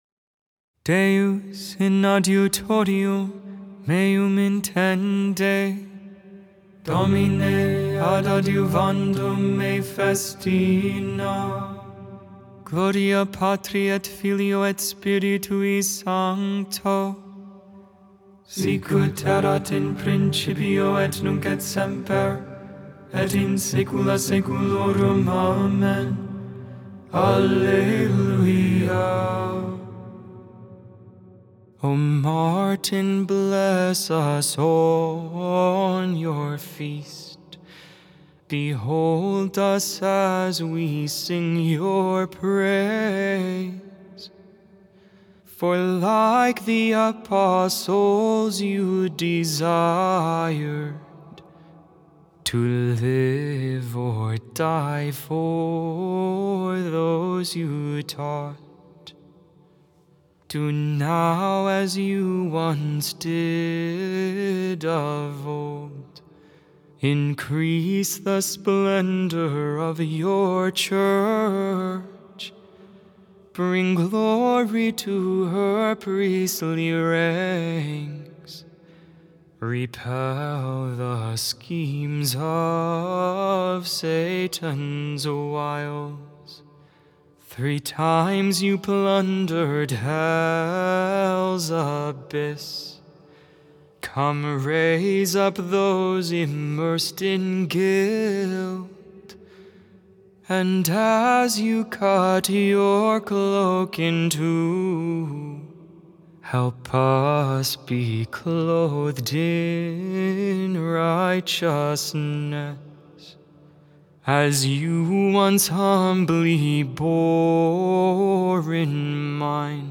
The Liturgy of the Hours: Sing the Hours 11.11.24 Lauds, Monday Morning Prayer Nov 10 2024 | 00:17:05 1x 00:00 / 00:17:05 Subscribe Share Spotify RSS Feed Share Link Embed